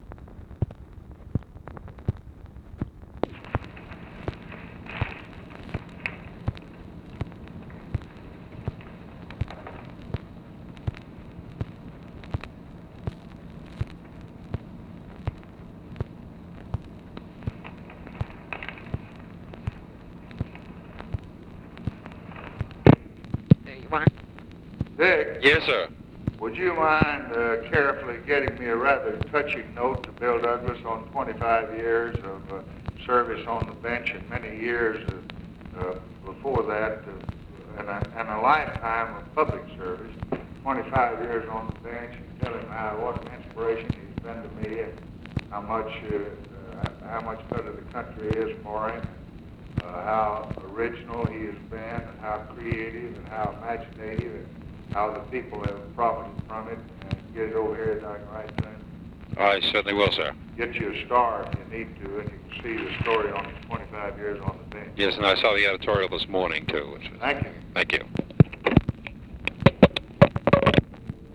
Conversation with RICHARD GOODWIN, April 17, 1964
Secret White House Tapes